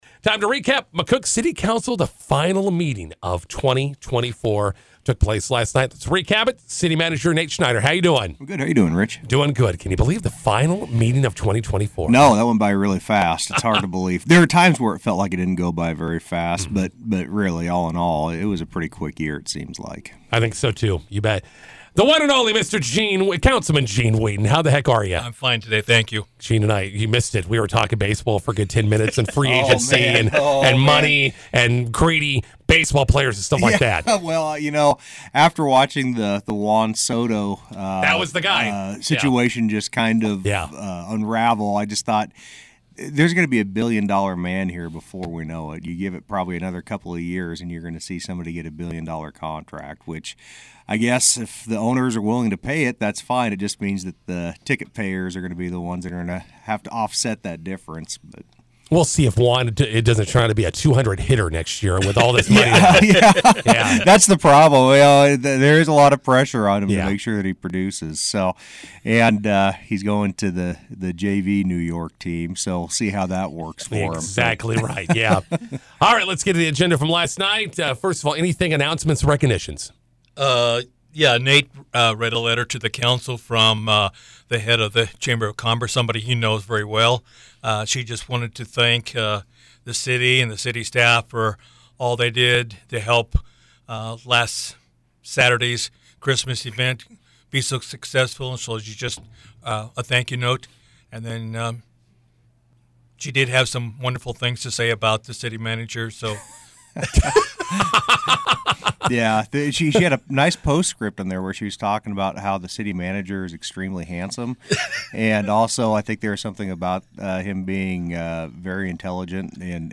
INTERVIEW: McCook City Council meeting recap with City Manager Nate Schneider and Councilman Gene Weedin.